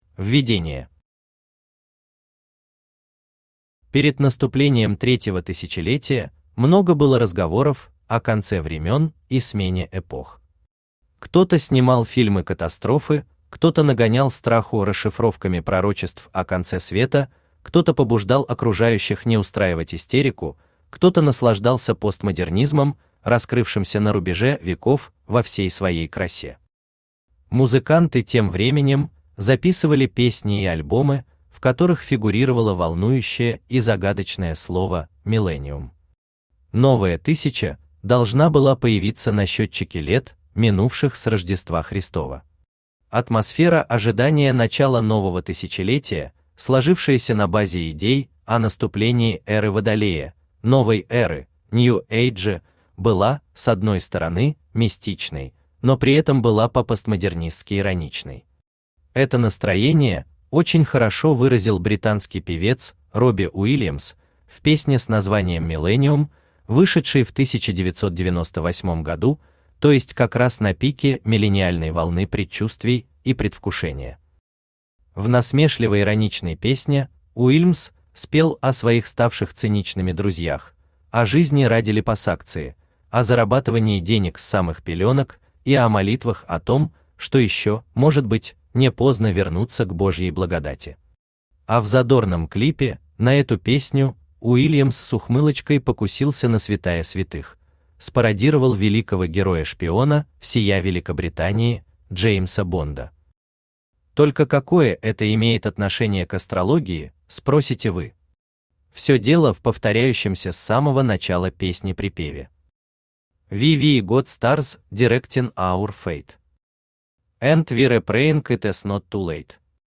Aудиокнига Астрология и наука Автор Маргарита Рефери Читает аудиокнигу Авточтец ЛитРес.